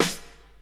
Royality free acoustic snare sample tuned to the G note. Loudest frequency: 2029Hz
• Old School Hip-Hop Acoustic Snare Sound G Key 52.wav
old-school-hip-hop-acoustic-snare-sound-g-key-52-pLn.wav